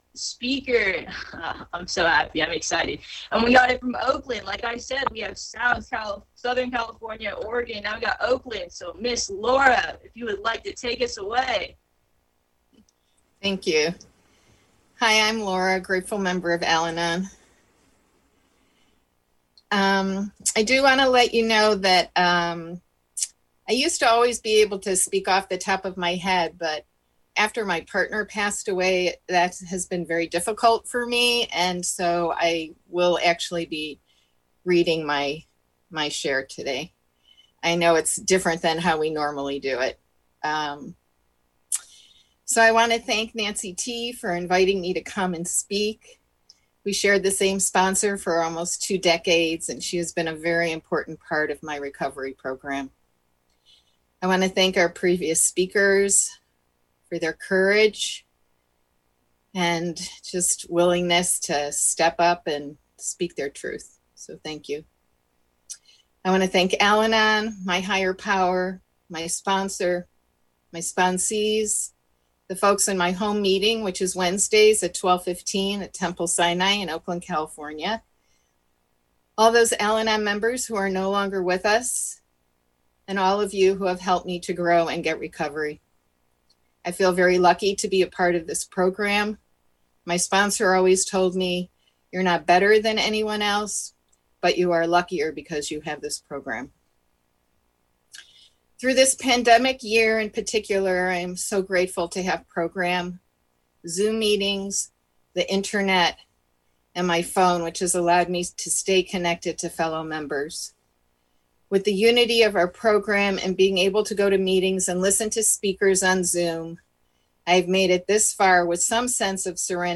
NCWSA 2021 Convention Fundraiser &#8211